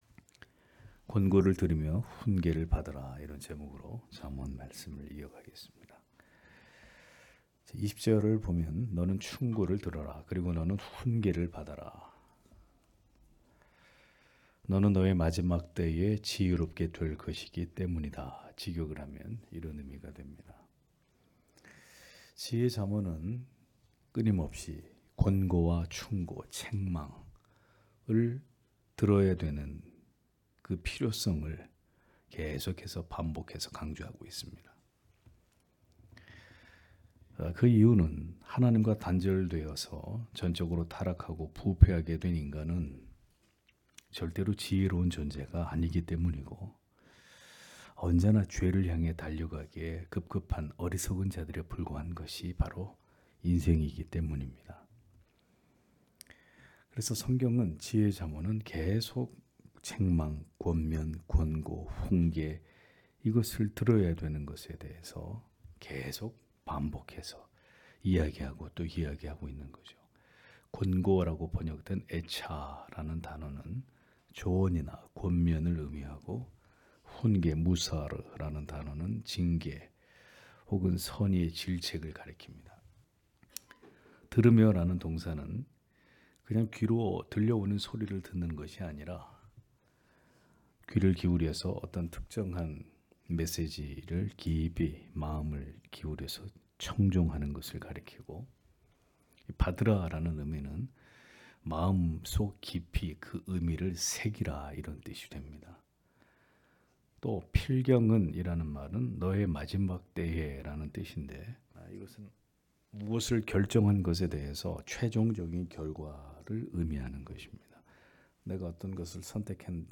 수요기도회 - [잠언 강해 118] 권고를 들으며 훈계를 받으라 (잠 19장 20-22절)